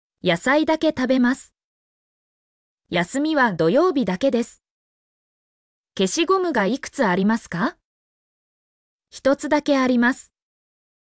mp3-output-ttsfreedotcom-49_wP8hbxP6.mp3